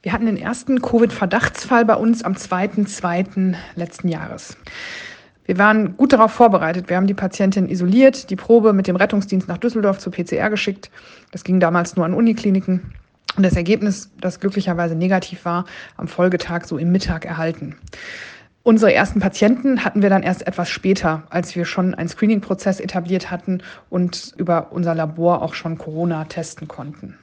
Virologin